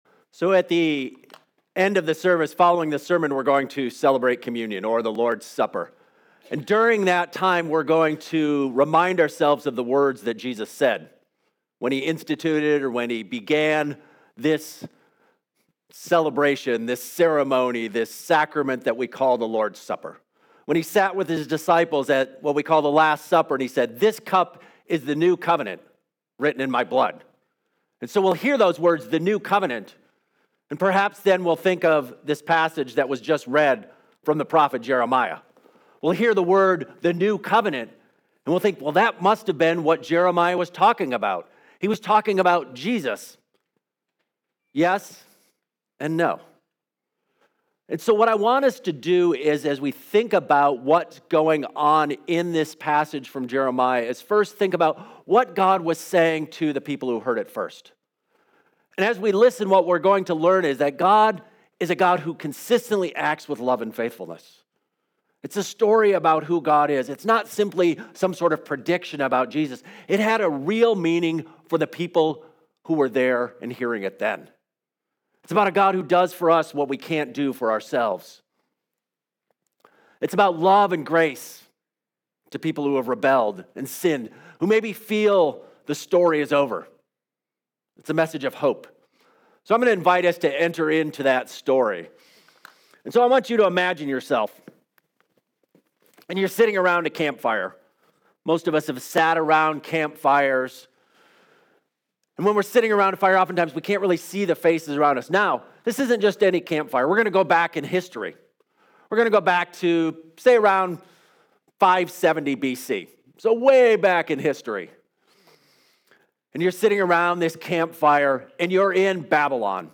Sermons | Fruitland Covenant Church